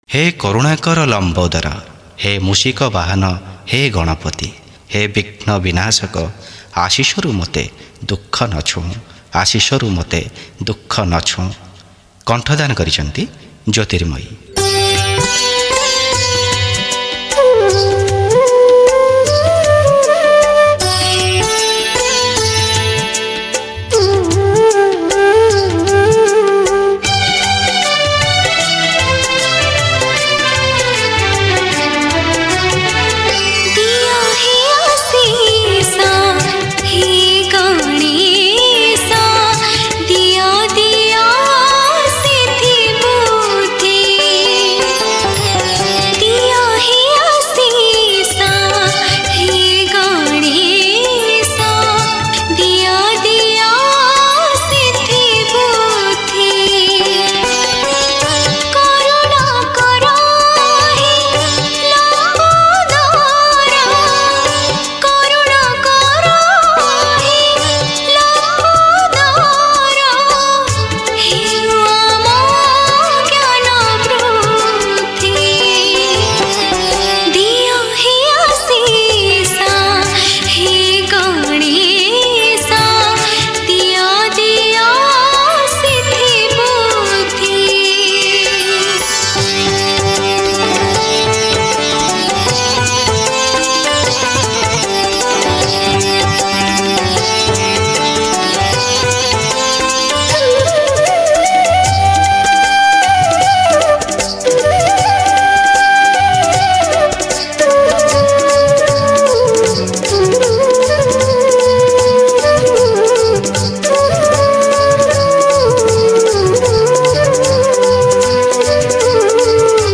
Category: Ganesh Puja Special Odia Songs